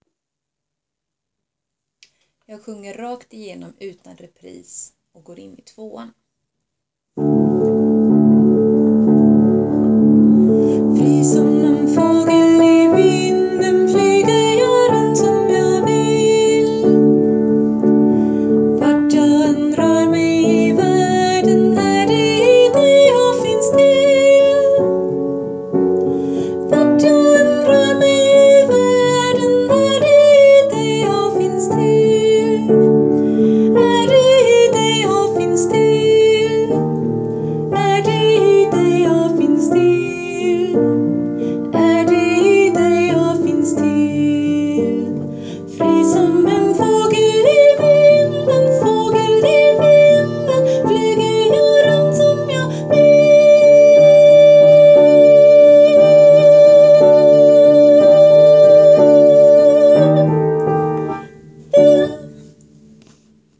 Fri som en fågel sop
fri som sop.wav